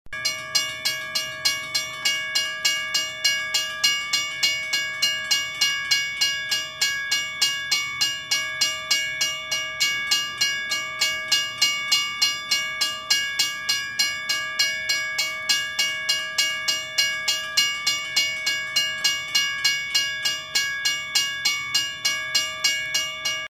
Звуки железнодорожного переезда
На этой странице собраны звуки железнодорожного переезда: сигнальные звонки, гудки локомотива, стук колес по рельсам.